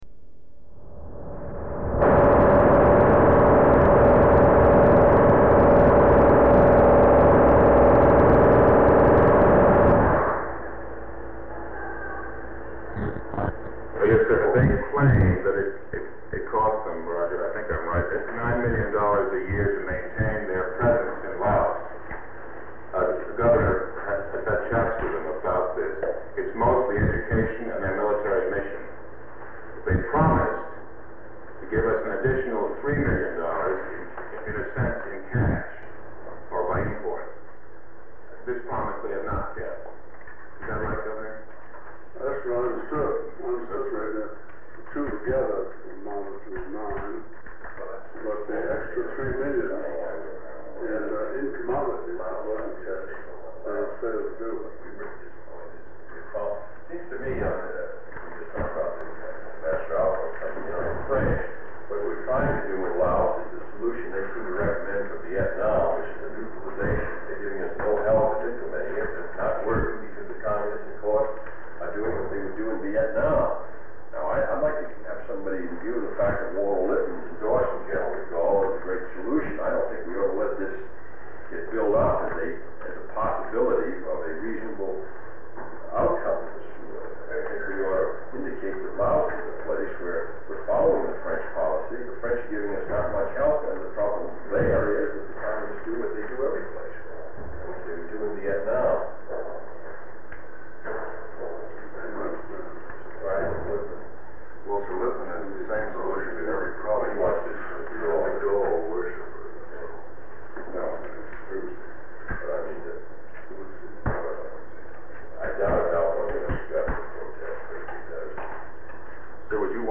This meeting is the continued discussion between President Kennedy and a wide range of Department of State, Department of Defense, CIA, and White House advisors on the volatile situation in South Vietnam. This sound recording has been excerpted from Tape 108, which contains additional sound recording(s) preceding this one.
Secret White House Tapes | John F. Kennedy Presidency Meetings: Tape 108/A43.